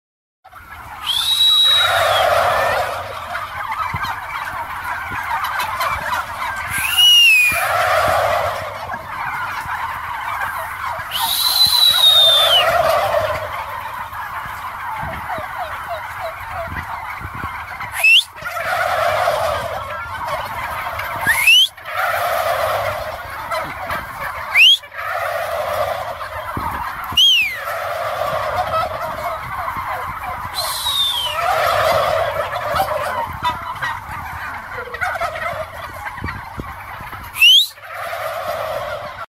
Turkey (animal)
— An army of turkeys
GOBBLEGOBBLEGOBBLE.mp3